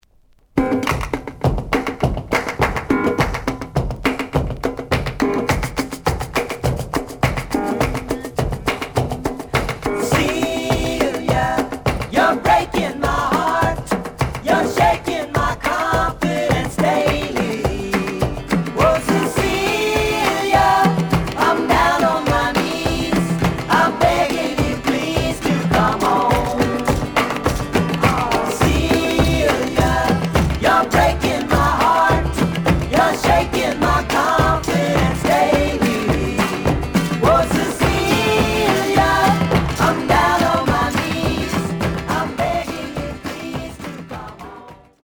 The audio sample is recorded from the actual item.
●Genre: Rock / Pop
A side plays good.